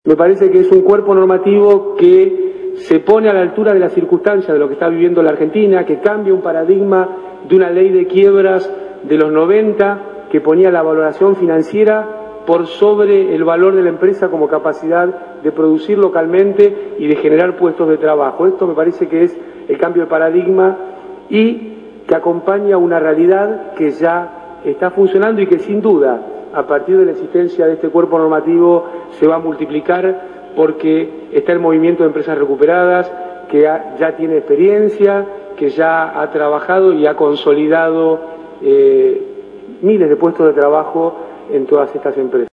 En una conferencia de prensa realizada en Casa de Gobierno, se anunció la promulgación de la reforma a la ley de quiebras.